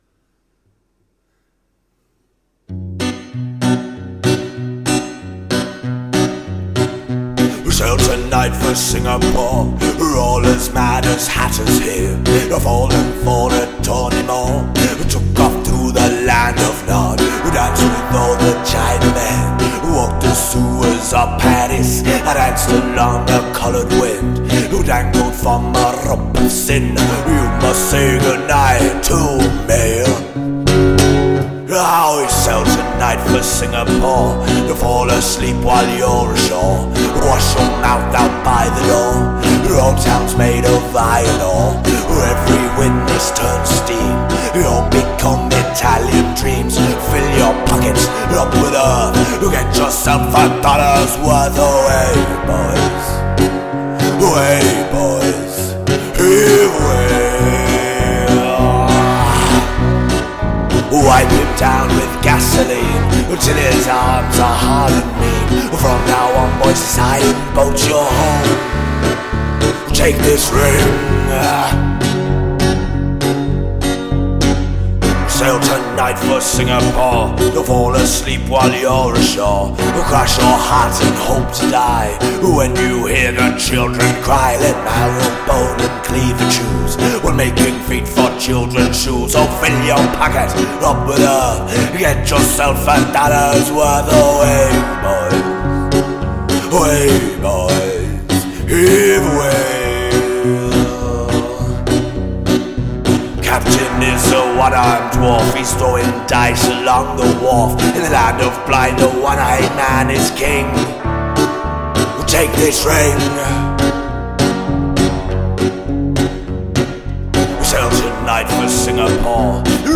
Experimental Rock , Blues , Street Music